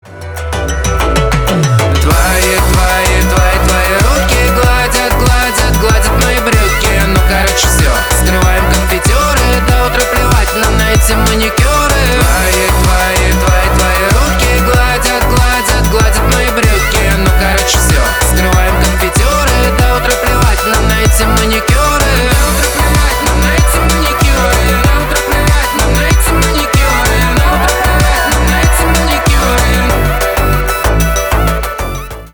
веселые , громкие
поп